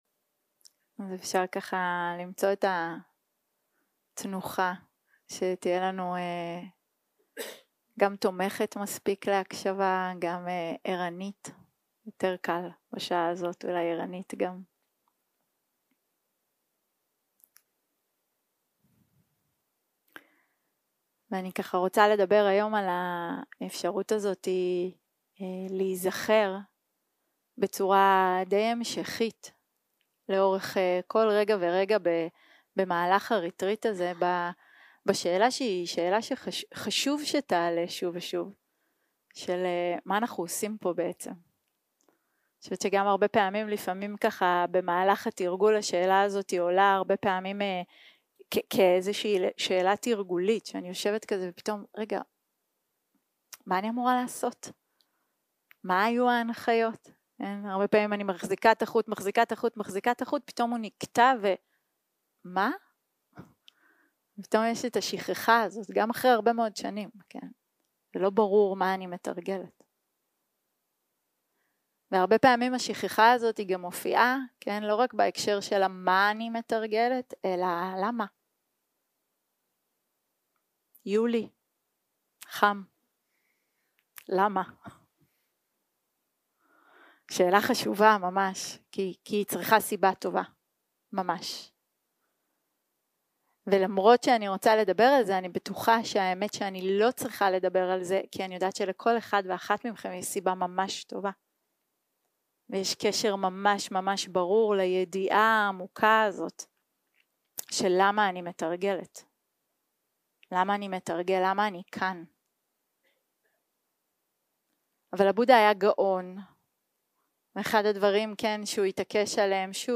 Dharma type: Dharma Talks שפת ההקלטה